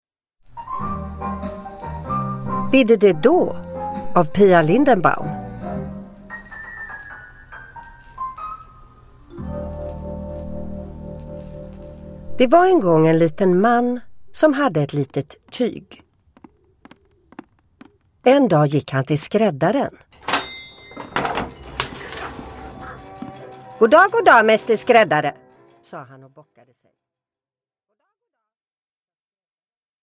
Bidde det då? : sagan Mäster skräddare – Ljudbok – Laddas ner
Uppläsare: Sissela Kyle